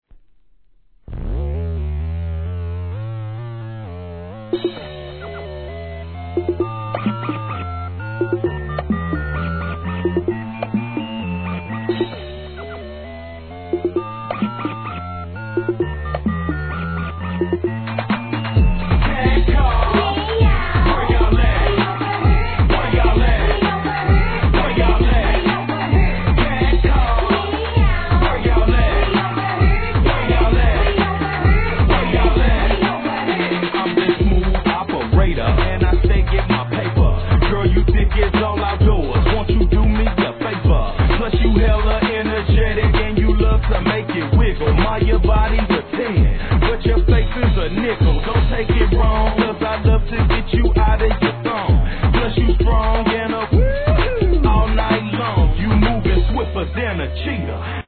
HIP HOP/R&B
西海岸アンダーグランド